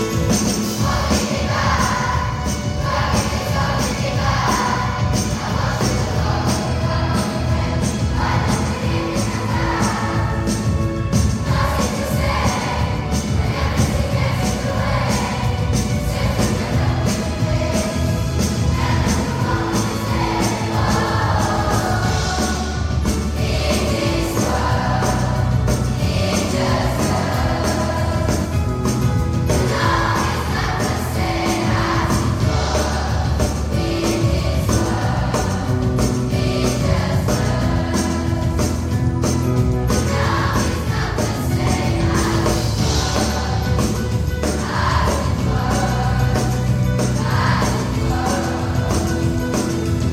Après une année de travail hebdomadaire et de répétition, les élèves de la chorale du collège se sont produits dans la joie et la bonne humeur le mardi 3 juin à la salle de La Coupole à Saint Loubes dans le cadre de la saison des concerts de l’association chante école.